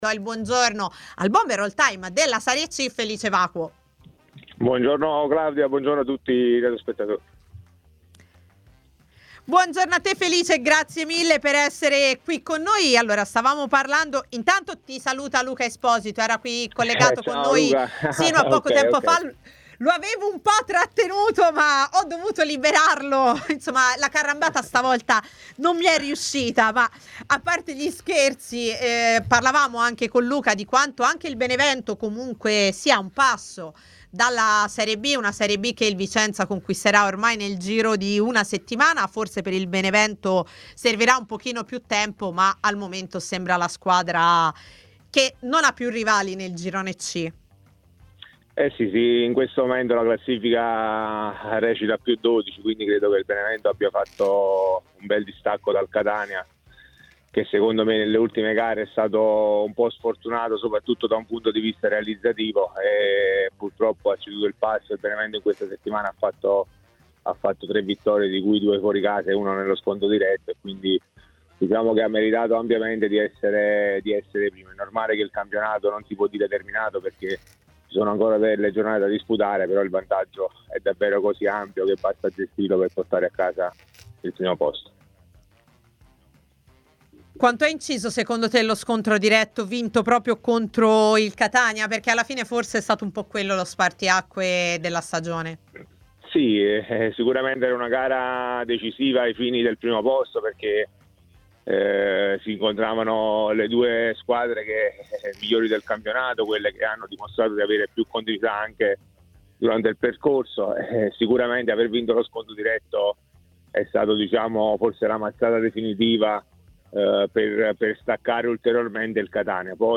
A Tutta C', trasmissione in onda su TMW Radio e iL61